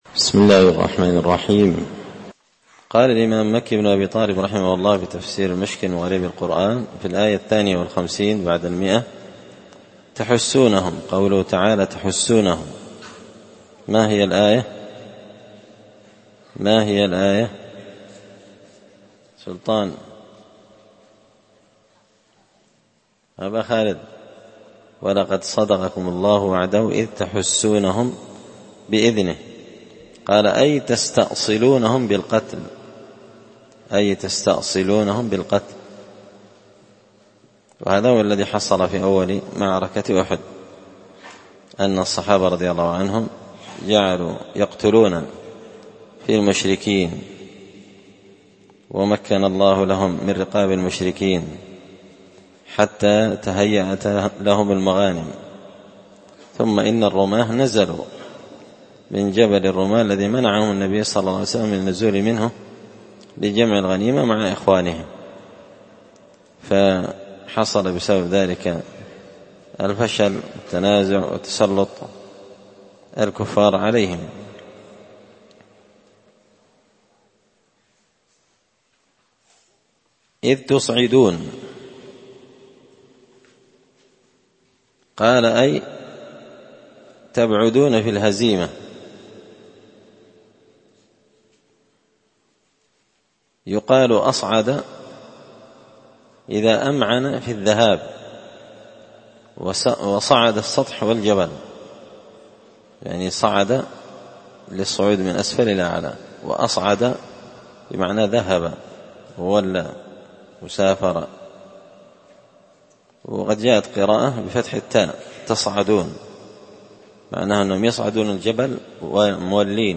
تفسير المشكل من غريب القرآن ـ الدرس 75